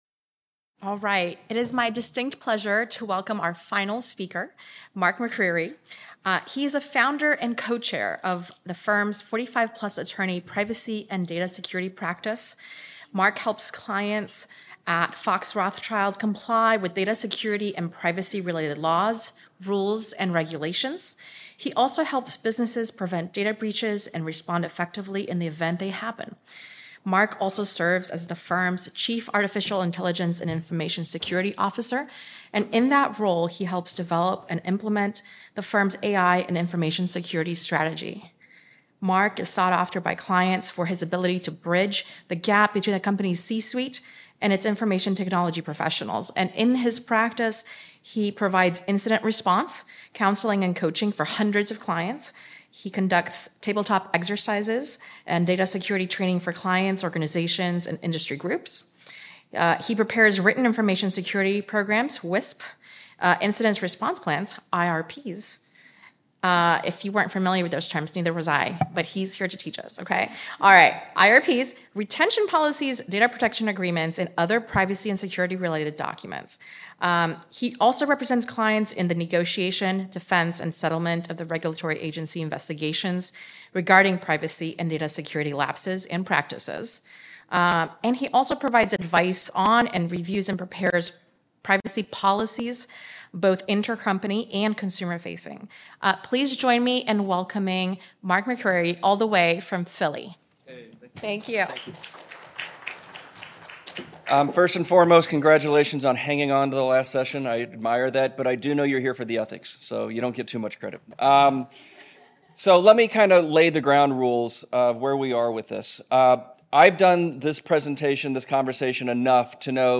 Originally presented: May 2024 Technology Law Conference